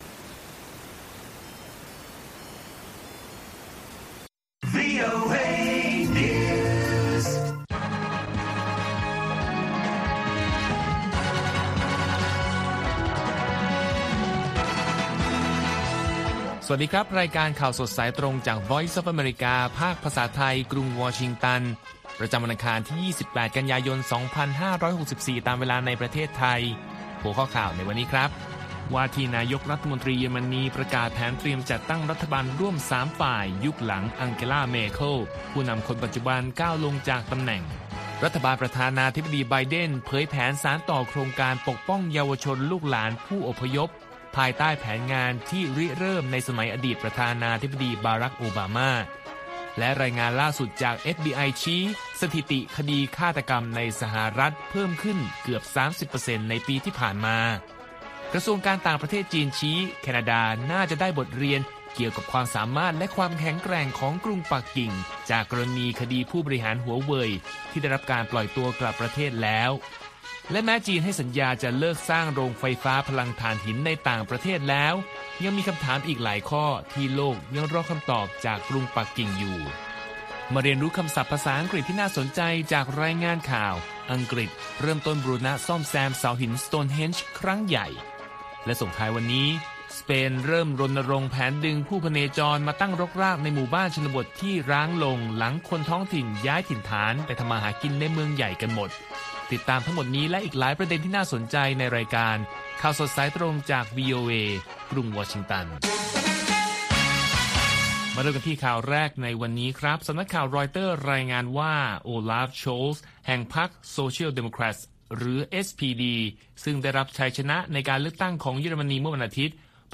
ข่าวสดสายตรงจากวีโอเอ ภาคภาษาไทย ประจำวันอังคารที่ 28 กันยายน 2564 ตามเวลาประเทศไทย